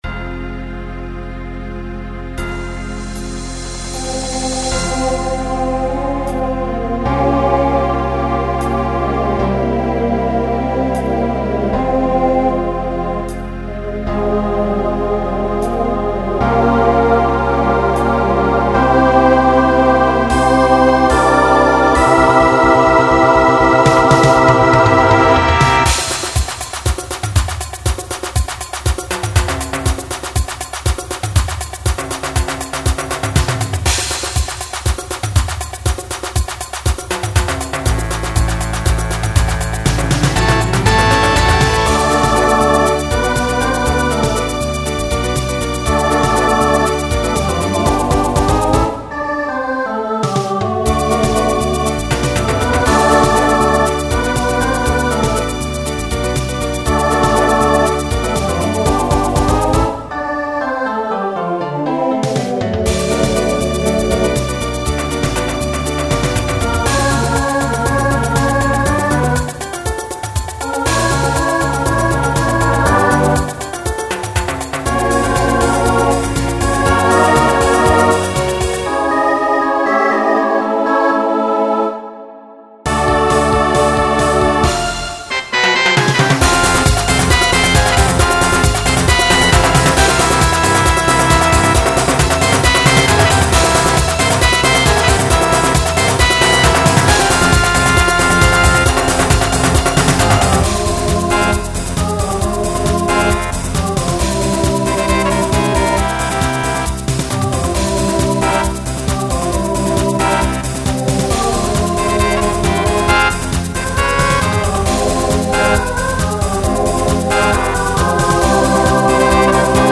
Genre Broadway/Film , Latin , Pop/Dance Instrumental combo
Story/Theme Voicing SATB